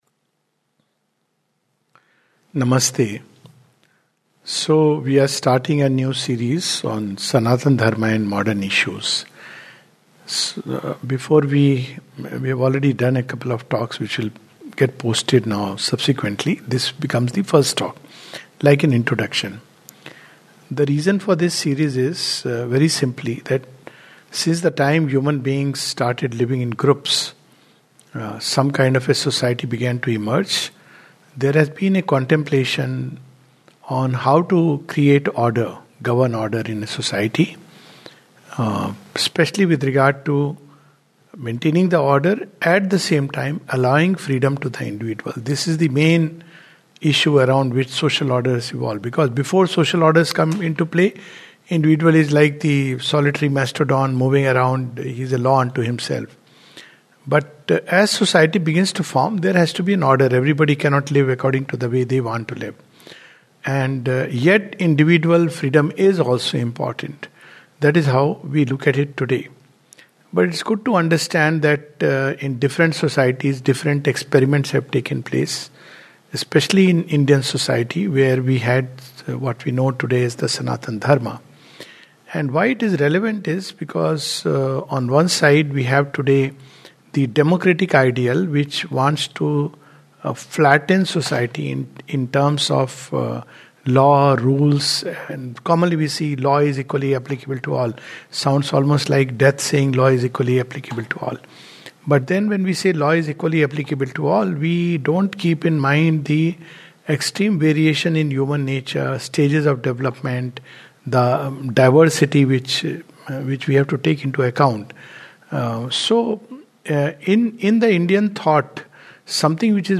This is the first talk of a new series on Sanatan Dharma and Modern Issues.